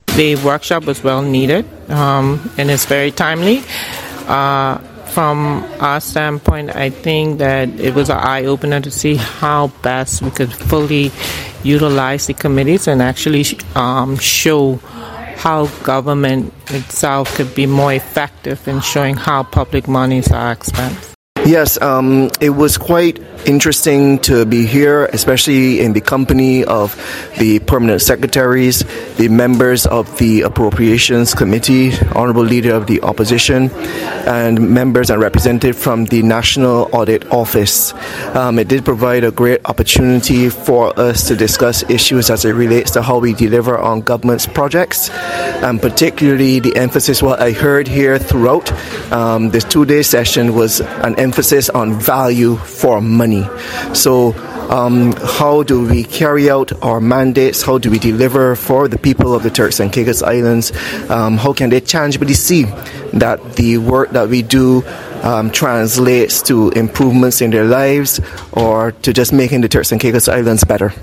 During the workshop there were members of parliament present, permanent secretaries, and other accounting officials. We got to hear some of their thoughts on the effectiveness of the workshop.